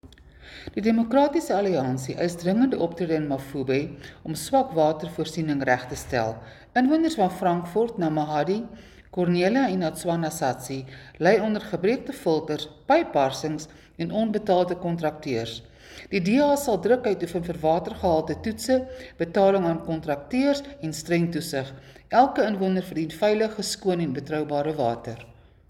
Afrikaans soundbites by Cllr Suzette Steyn and Sesotho soundbite by Cllr Kabelo Moreeng.